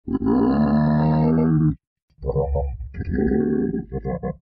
Giraffe Sound Button - Free Download & Play
Animal Sounds Soundboard184 views